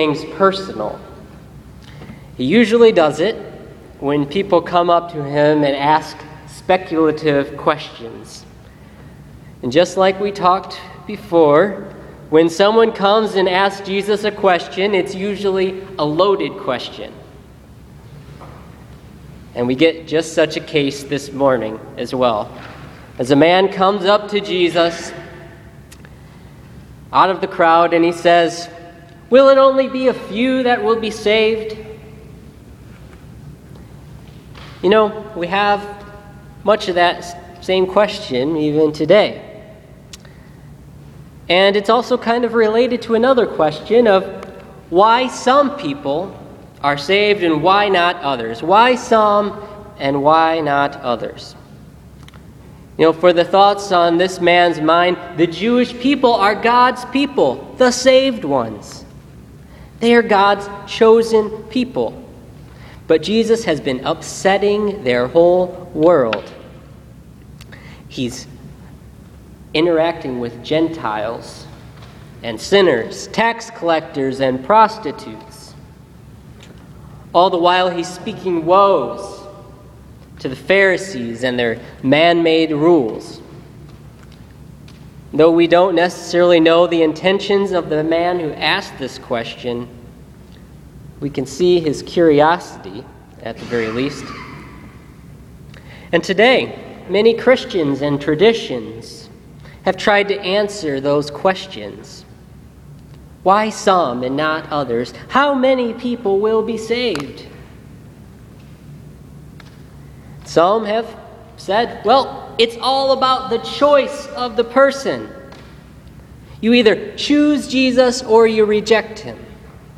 Listen to this week’s sermon on Luke 13:22-30 for the 14th Sunday after Pentecost.